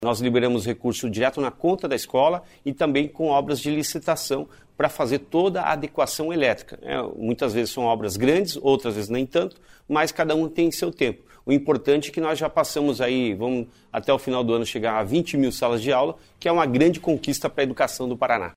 Sonora do secretário Estadual da Educação, Roni Miranda, sobre a volta as aulas para o 2º semestre letivo